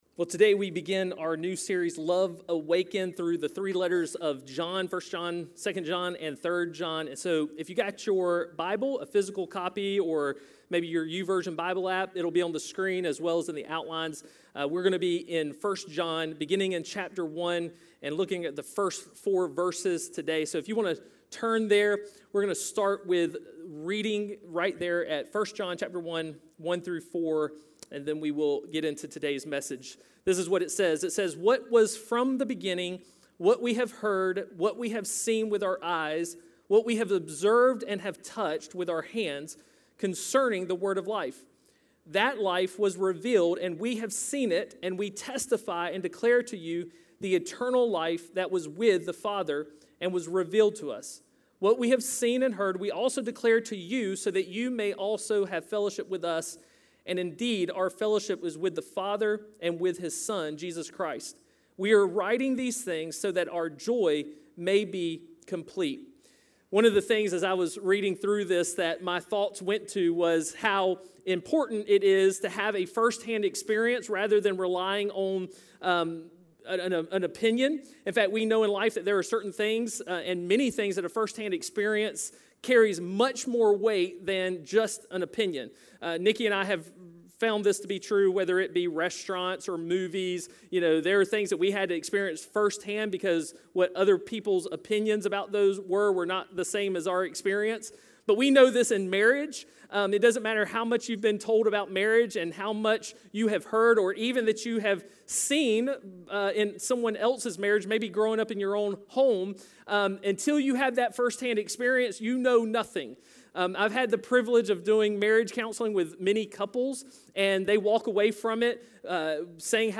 A message from the series "No Rival."